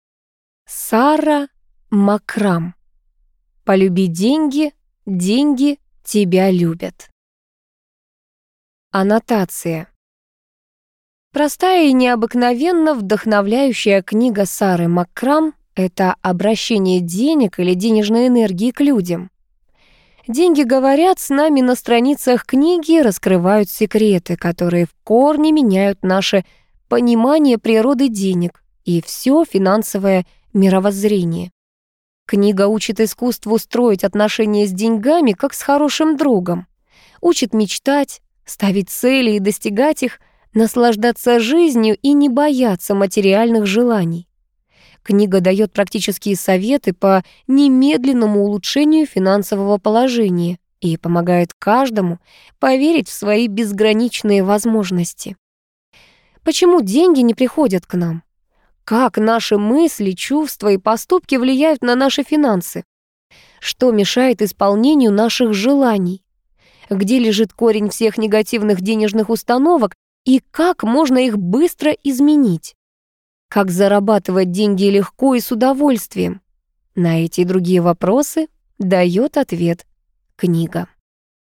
Аудиокнига Полюби деньги – деньги тебя любят | Библиотека аудиокниг